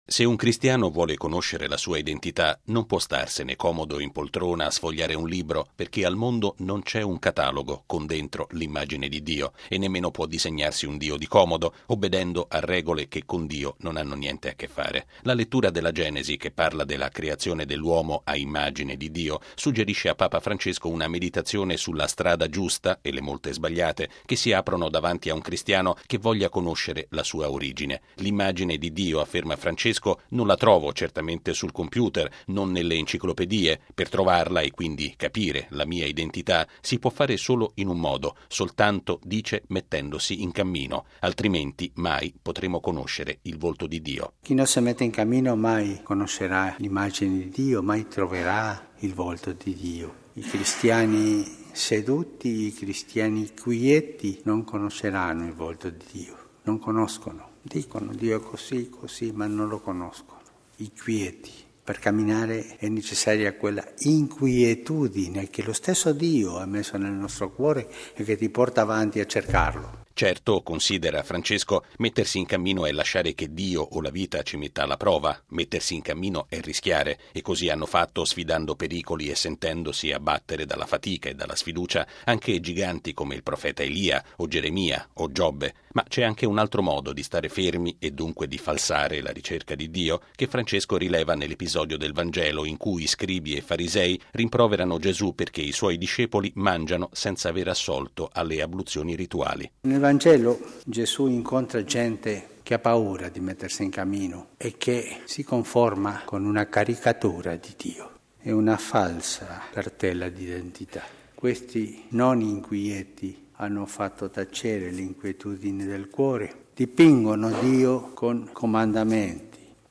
Per incontrare Dio bisogna rischiare e mettersi in cammino, perché un cristiano “quieto” non potrà “mai conoscere” il volto del Padre. È la riflessione che Papa Francesco ha sviluppato durante l’omelia della Messa del mattino, celebrata nella cappella di Casa S. Marta.